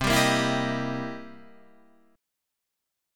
C7#9 chord